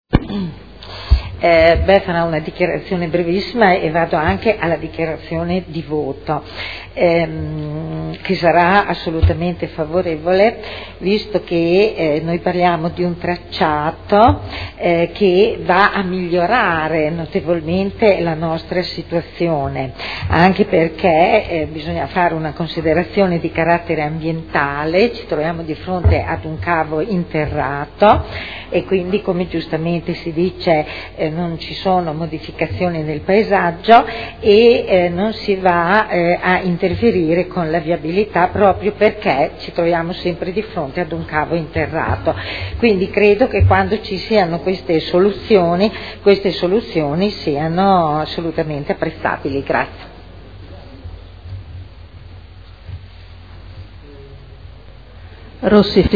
Seduta del 23/05/2011. Dibattito su Proposta di variante al POC per la pianificazione di un tracciato di linea elettrica interrata a 15 KV denominato cavo Maserati – Parere favorevole (Commissione consiliare del 17 maggio 2011)